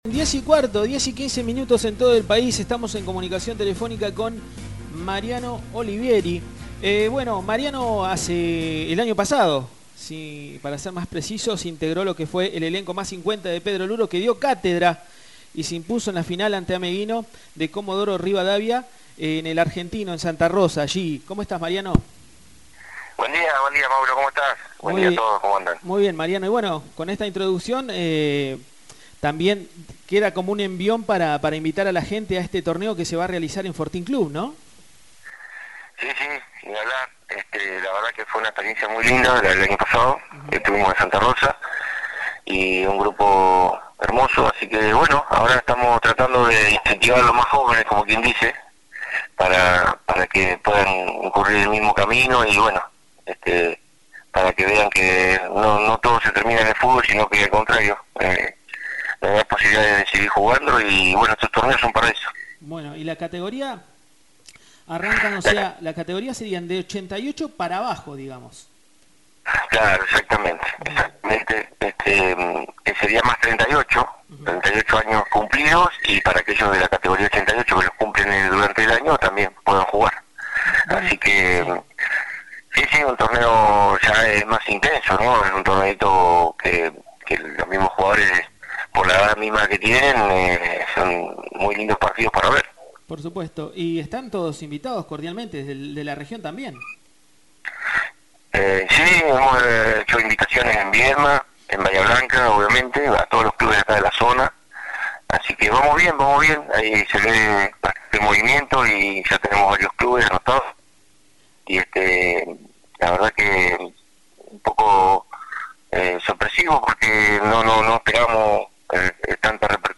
Escuchá la nota completa a continuación.